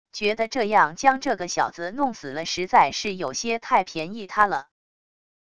觉得这样将这个小子弄死了实在是有些太便宜他了wav音频生成系统WAV Audio Player